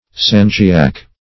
sangiac - definition of sangiac - synonyms, pronunciation, spelling from Free Dictionary Search Result for " sangiac" : The Collaborative International Dictionary of English v.0.48: Sangiac \San"gi*ac\, n. See Sanjak .